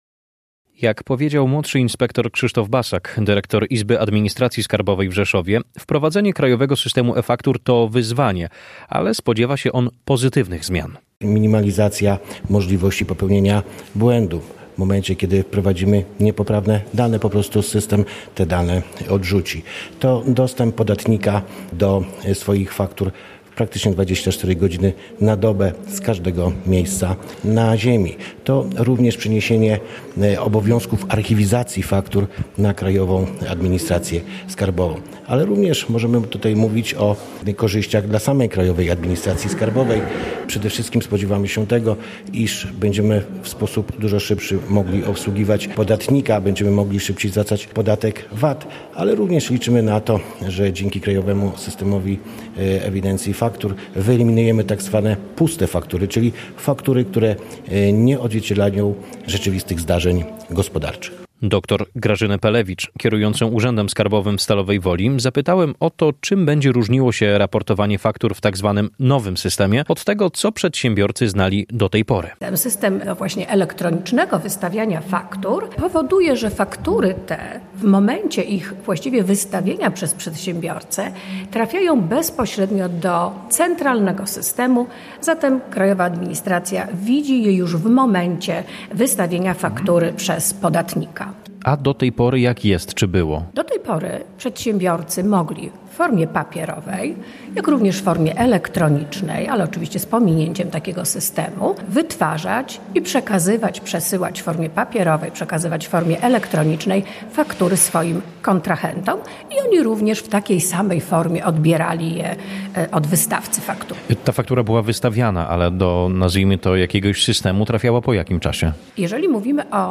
Podczas dzisiejszej konferencji w WSPiA Rzeszowskiej Szkole Wyższej dyskutowano o zmianach związanych z Krajowym Systemem e-Faktur.